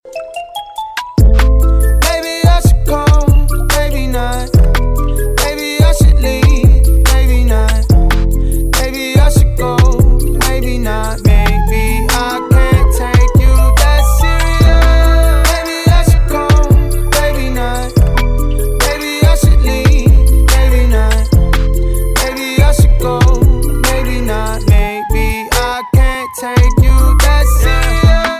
• Качество: 320, Stereo
мужской вокал
спокойные
Hiphop